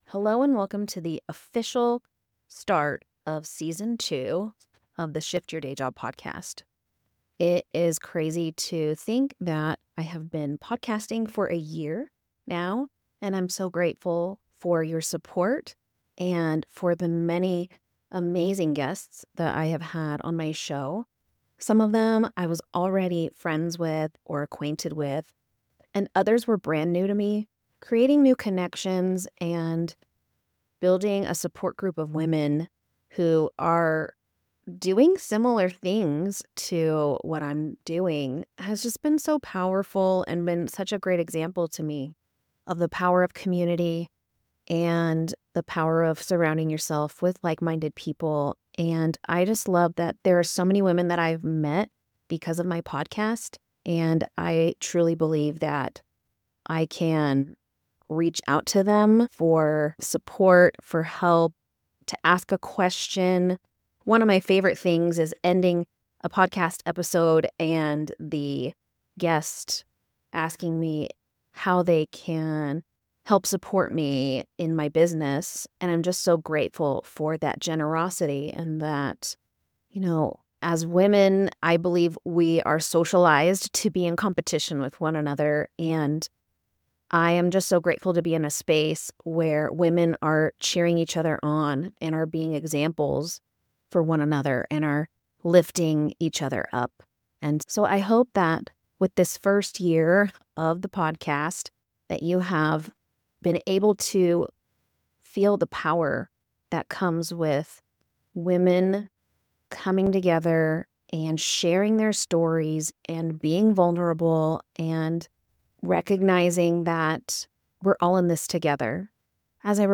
In this solo episode, I’m reflecting on a full year of podcasting—how it started, how it’s changed me, and why the power of community has been so impactful.